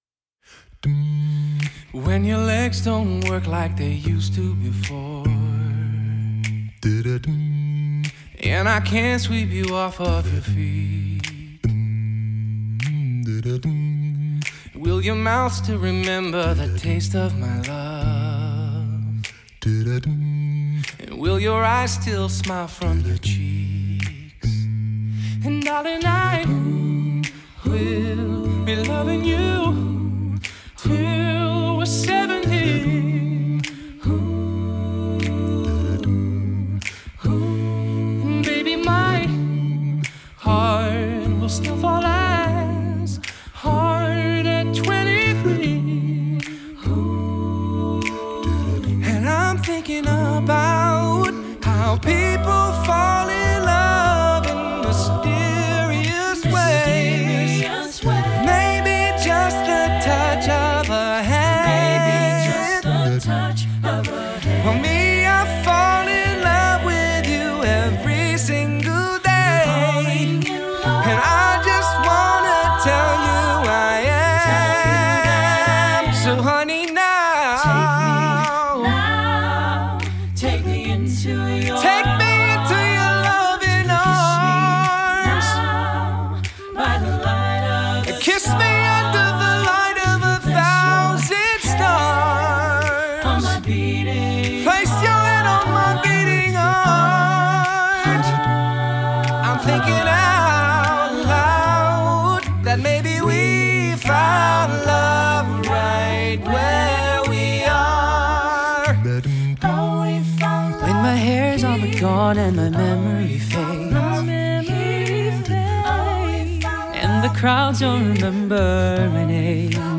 Genre: Pop, Classical